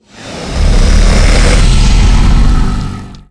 sucker_growl_3.ogg